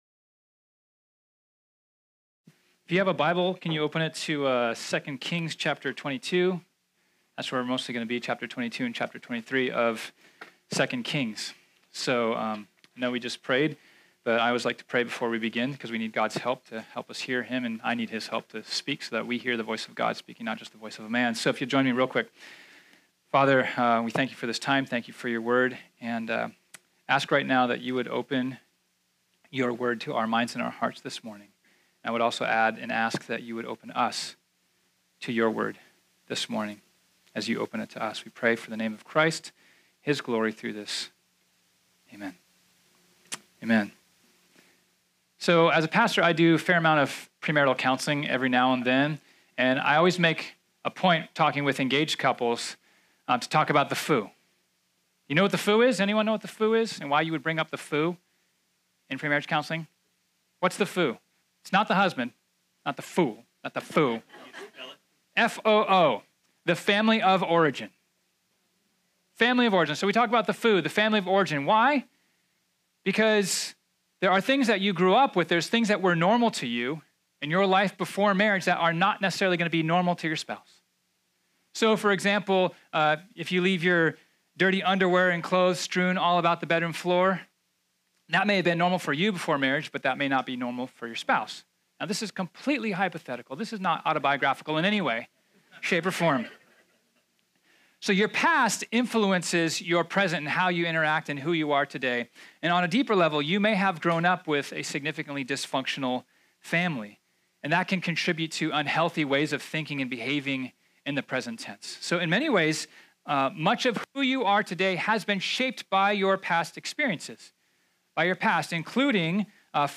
This sermon was originally preached on Sunday, August 12, 2018.